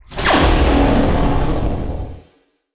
SPACE_WARP_Complex_03_stereo.wav